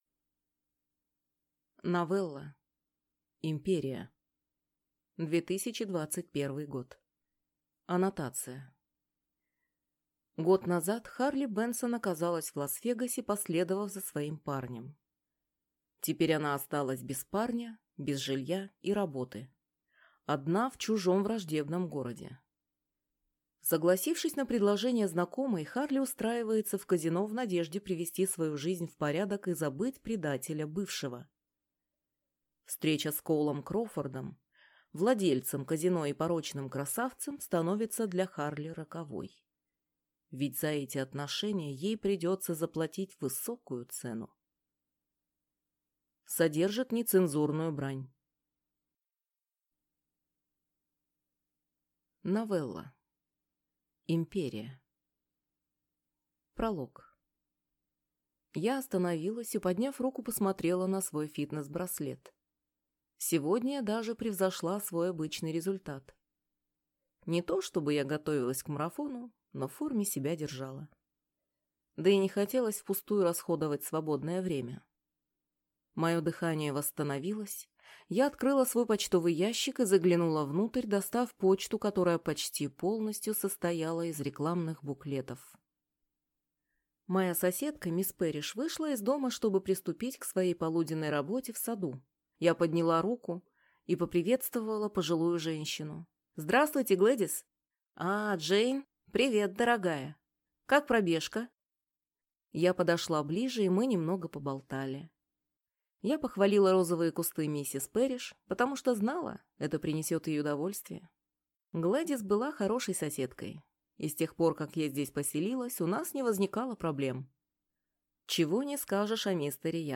Аудиокнига Империя | Библиотека аудиокниг